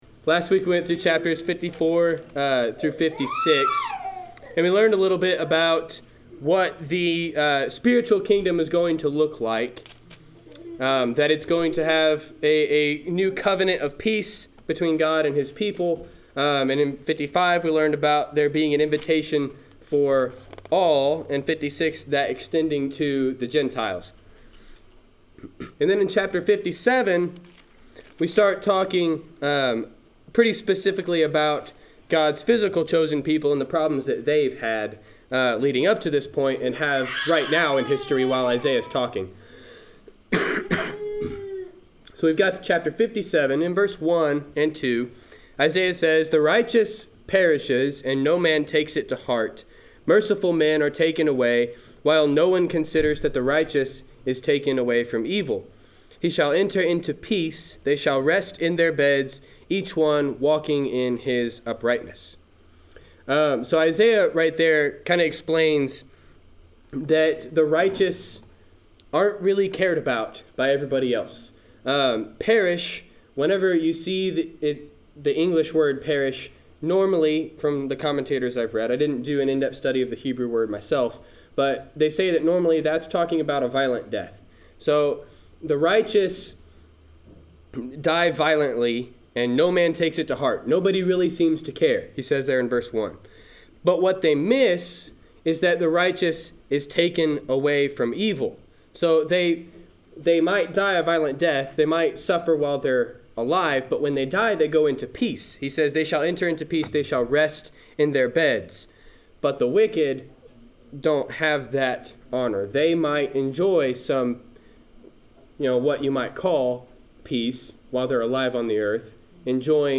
Wednesday Night Class